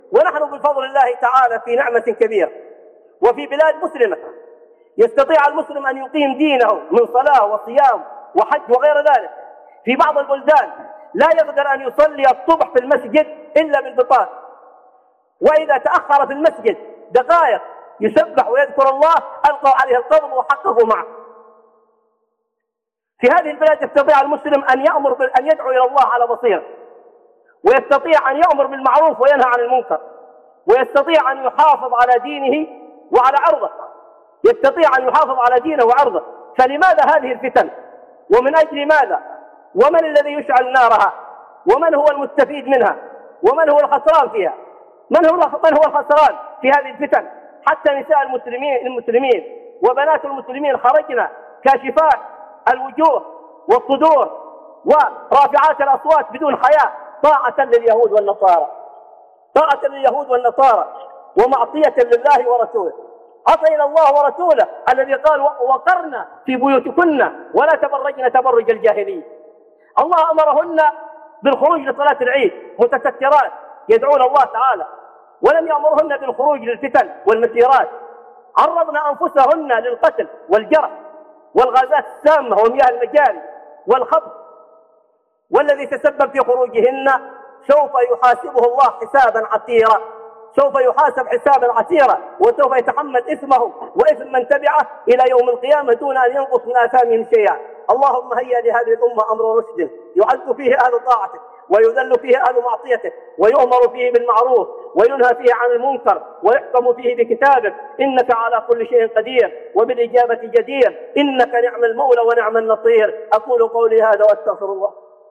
لماذا الفتن والمظاهرات؟ - خطب